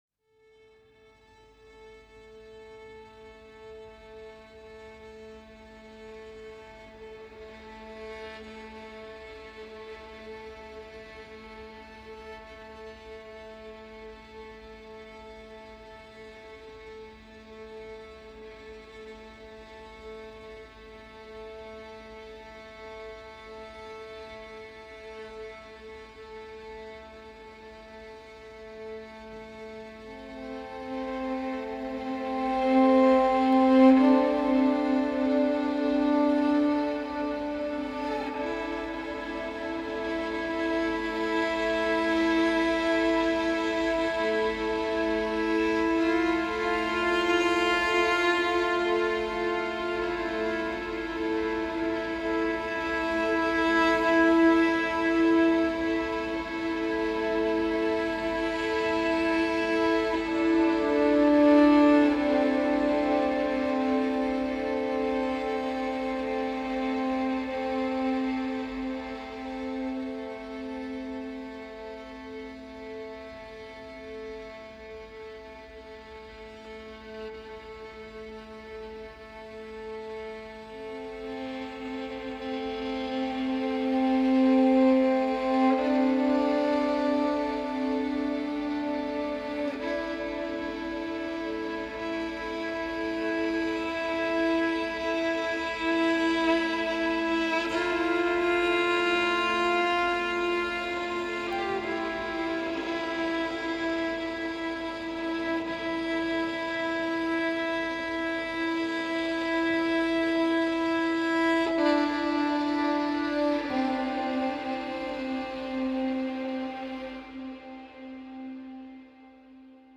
String Drone Version